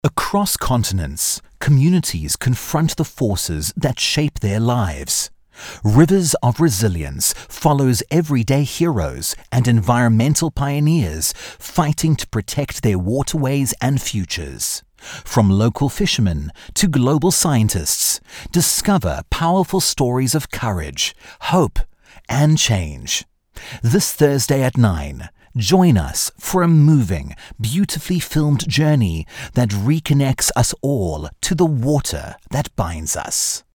Herzlich, sanft und vielseitig.